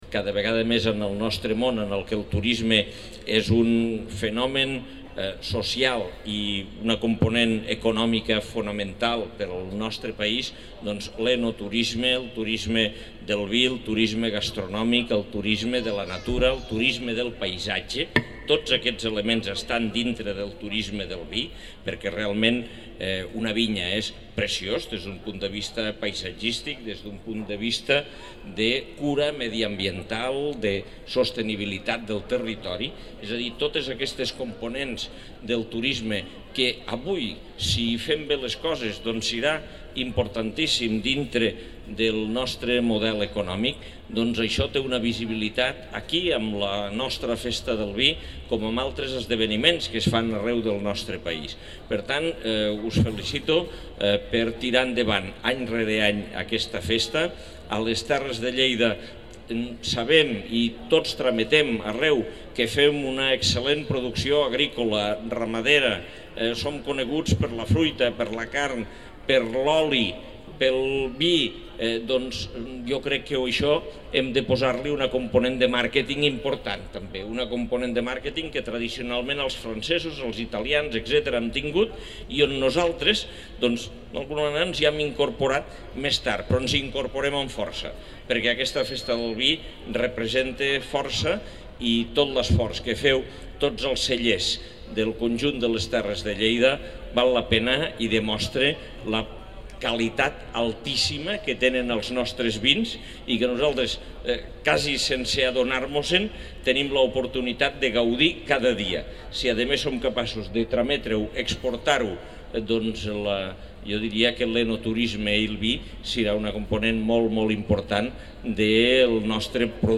Tall de veu de l'alcalde, Àngel Ros, sobre la 4a Festa del Vi de Lleida (1.9 MB) Fotografia 1 amb major resolució (2.7 MB) Fotografia 2 amb més resolució (2.5 MB) Fotografia 3 amb major resolució (2.2 MB) Fotografia 4 amb major resolució (1.8 MB)
tall-de-veu-de-lalcalde-angel-ros-sobre-la-4a-festa-del-vi-de-lleida